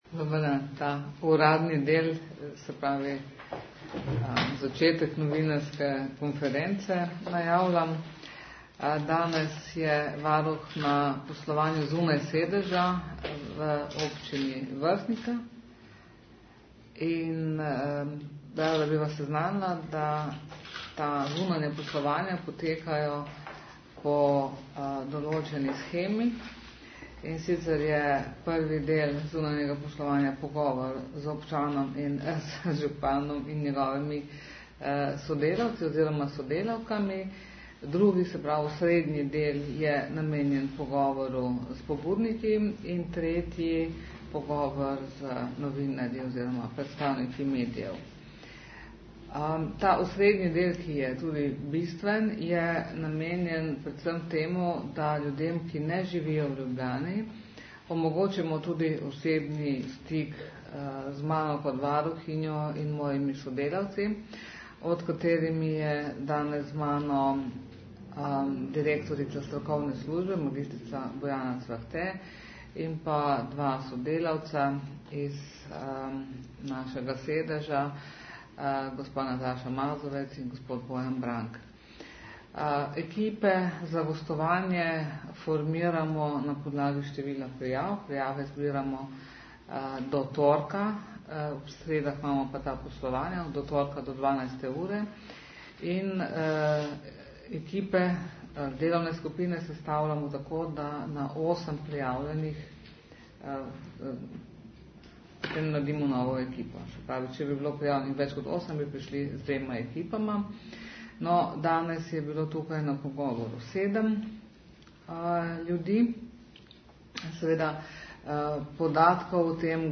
Varuhinja poslovala na Vrhniki - ZVOČNI POSNETEK NOVINARSKE KONFERENCE
Svoje ugotovitve je predstavila na krajši novinarski konferenci po pogovorih.